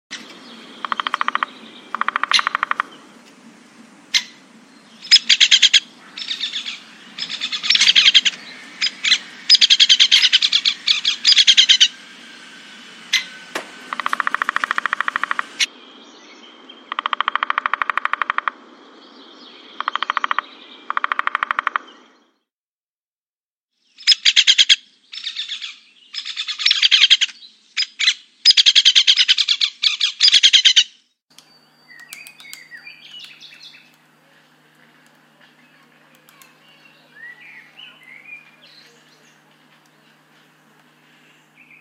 dzieciol.mp3